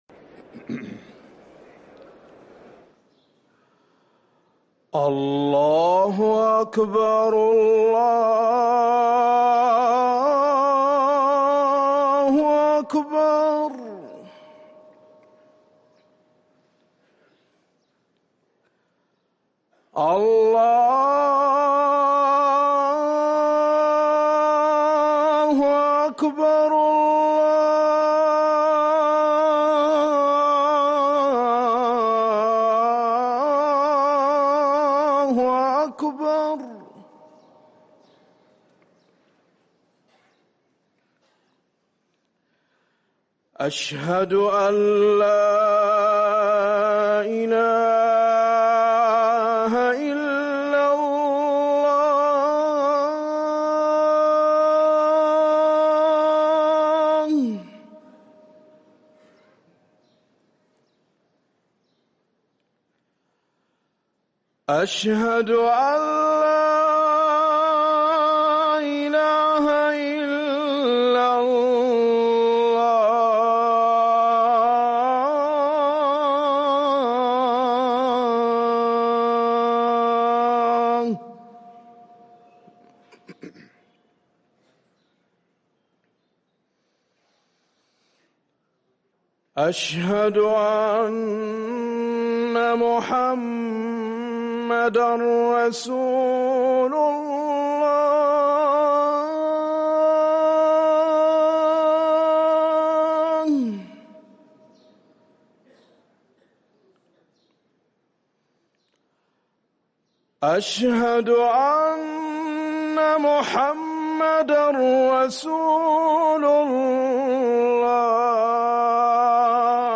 اذان العصر